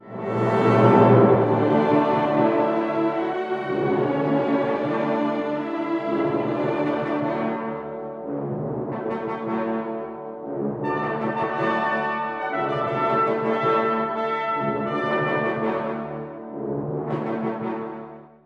力強く雄大な、ニ長調（D-dur）のフィナーレです。
そしてクライマックスでは、金管の賛歌のようなファンファーレが奏でられ、感動とともに幕を閉じます。